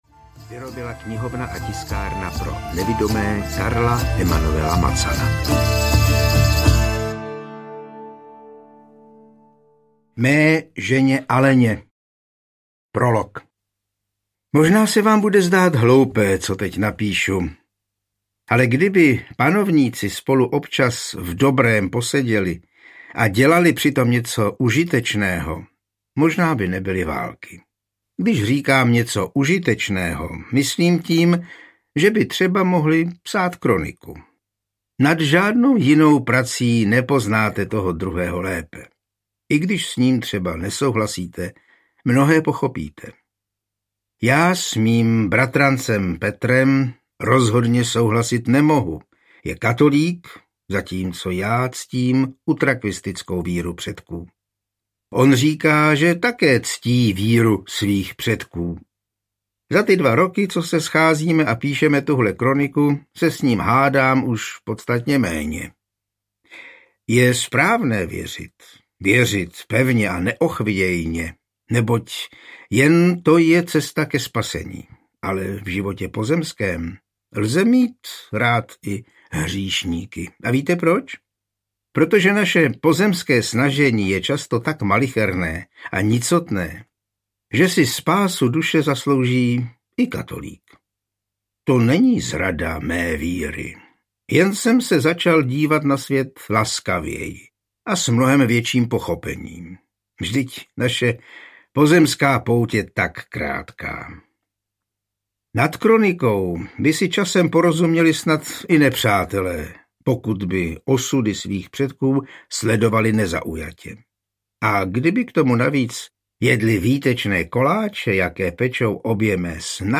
Husitská epopej IV audiokniha
Ukázka z knihy
• InterpretJan Hyhlík